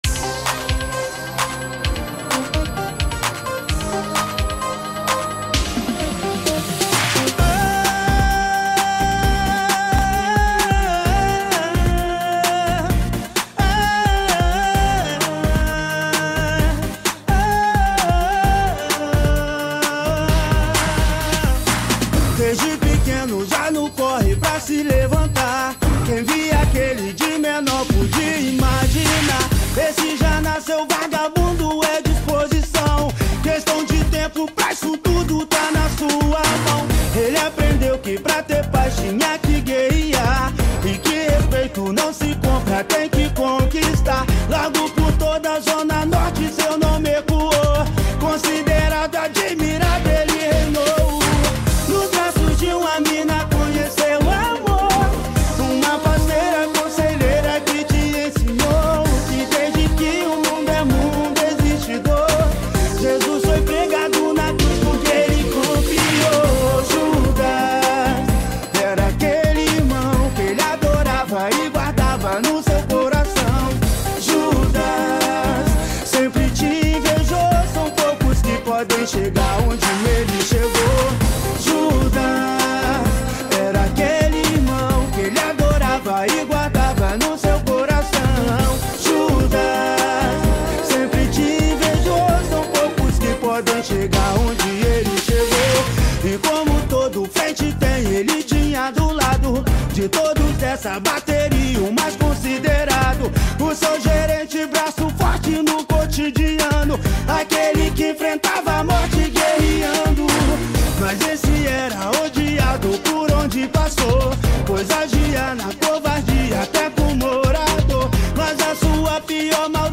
2025-04-07 08:31:07 Gênero: Rap Views